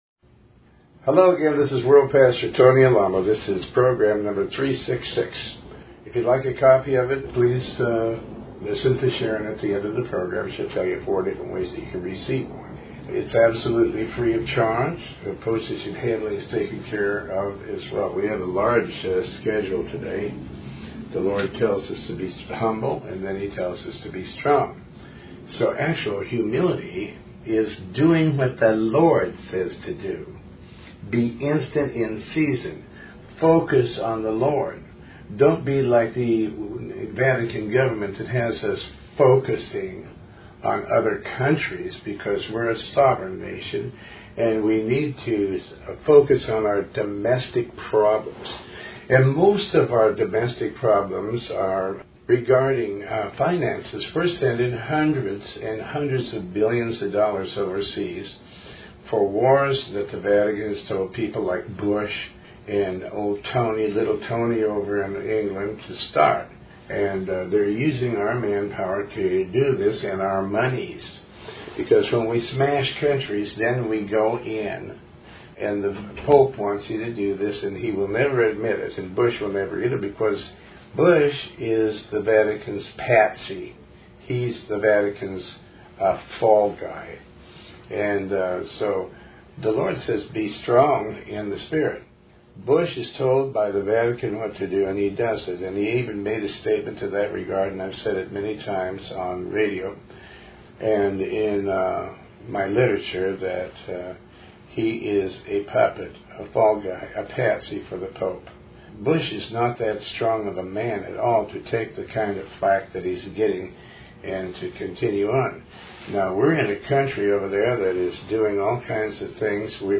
Talk Show
Show Host Pastor Tony Alamo